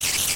assets / minecraft / sounds / mob / spider / say4.ogg